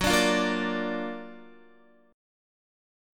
Gb+M7 chord